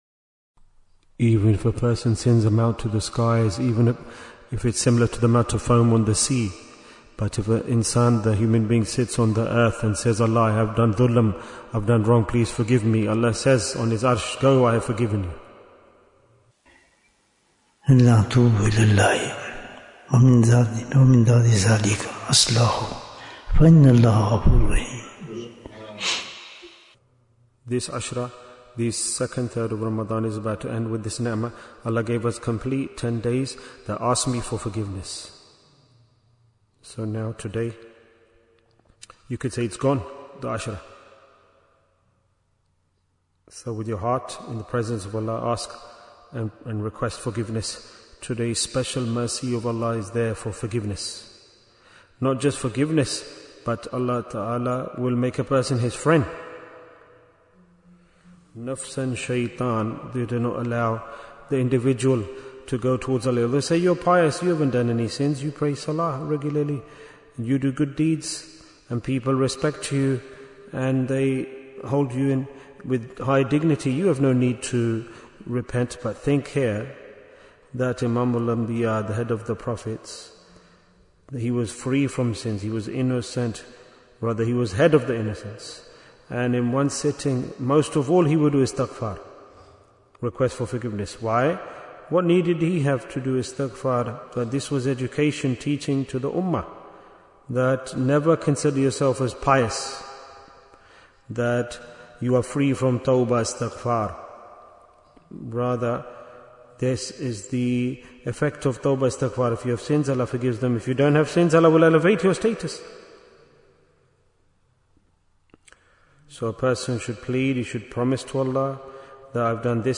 Why is Tazkiyyah Important? - Part 21 Bayan, 18 minutes8th March, 2026